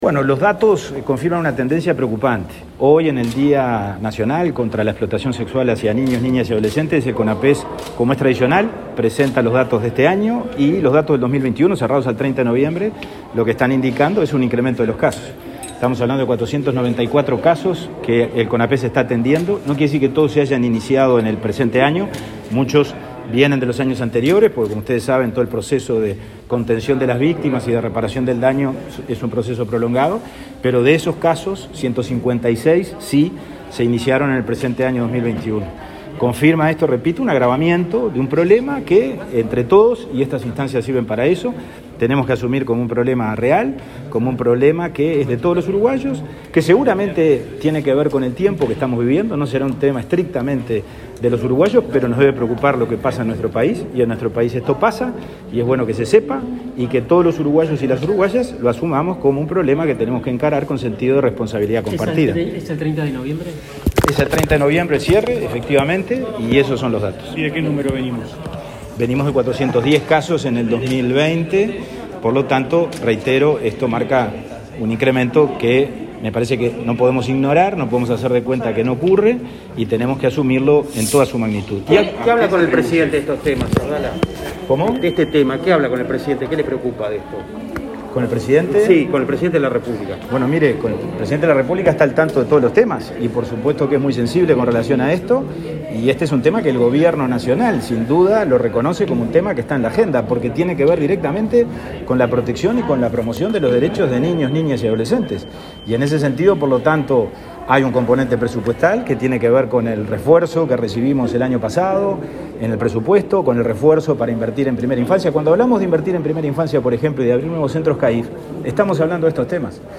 Declaraciones a la prensa del presidente de INAU, Pablo Abdala